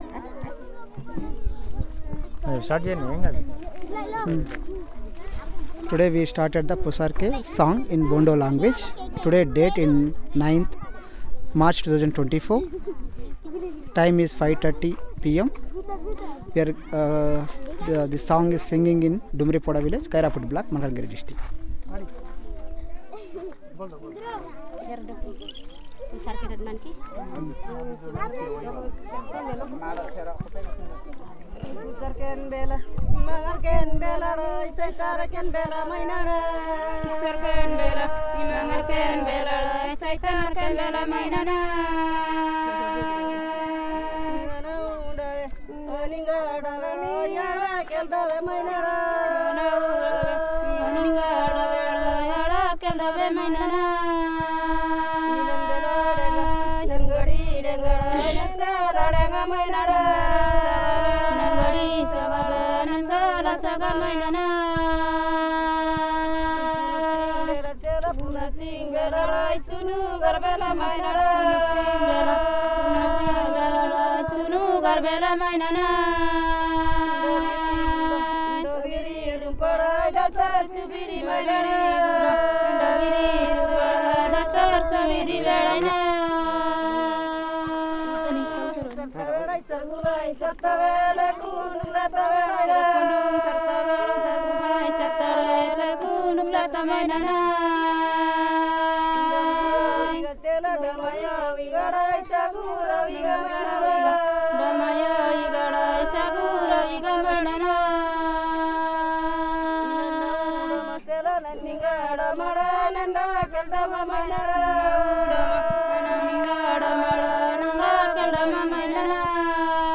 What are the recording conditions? Elicitation of a song associated with the Pusarke Festival